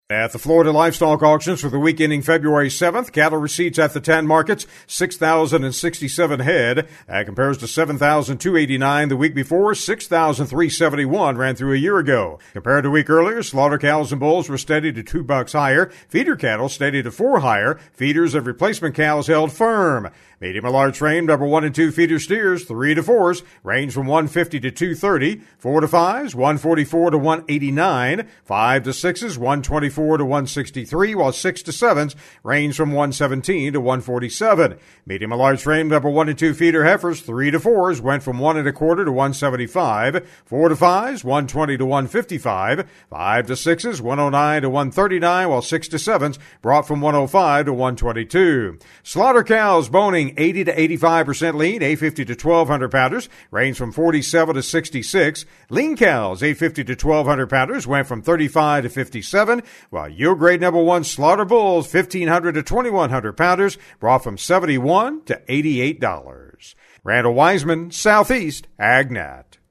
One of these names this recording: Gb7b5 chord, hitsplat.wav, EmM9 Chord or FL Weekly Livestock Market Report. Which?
FL Weekly Livestock Market Report